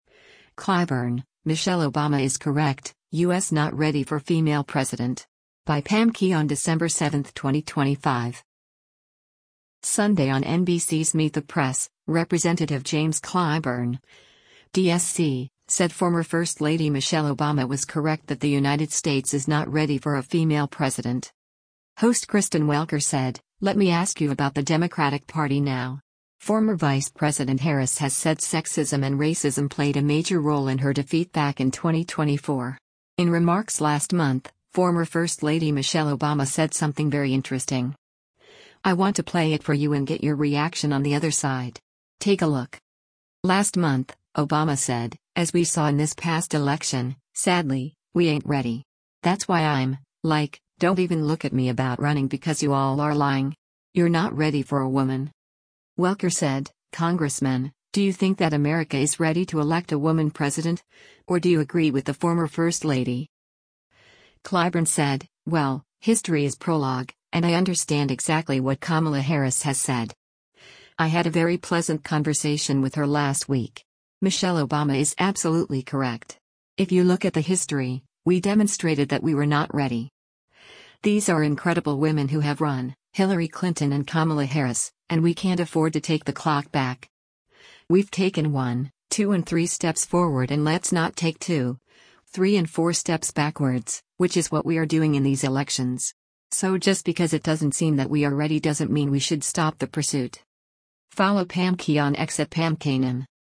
Sunday on NBC’s “Meet the Press,” Rep. James Clyburn (D-SC) said former first lady Michelle Obama was “correct” that the United States is “not ready” for a female president.